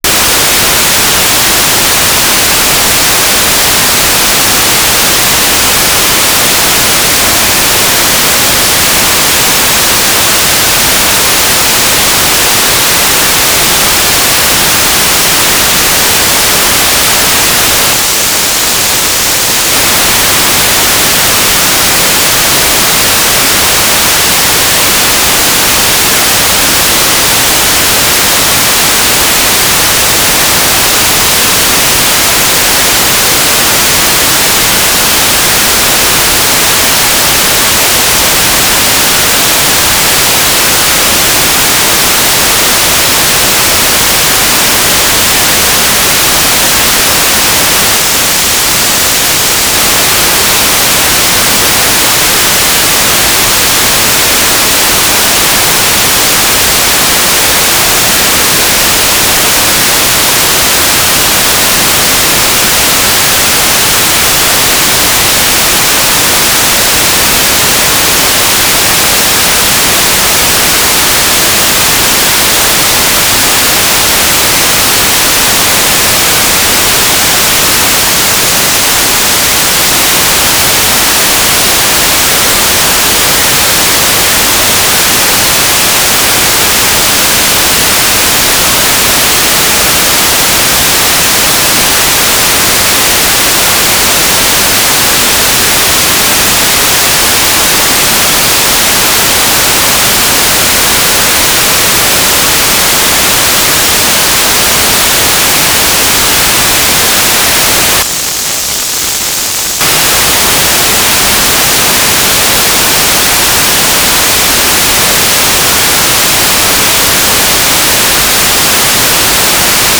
"transmitter_description": "Mode U - GMSK2k4 USP",
"transmitter_mode": "GMSK USP",